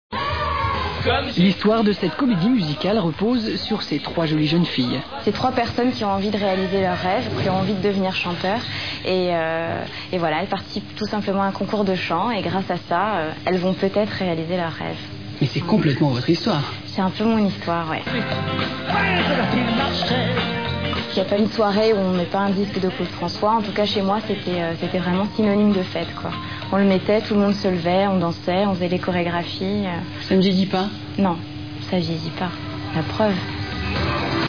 14 Octobre 2003Interview